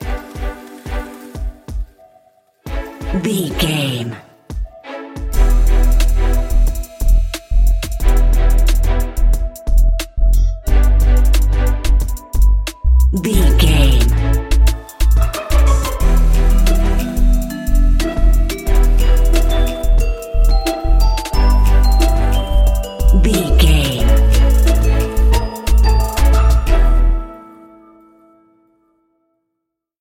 Aeolian/Minor
Slow
eerie
groovy
dark
synthesiser
drum machine
sleigh bells
strings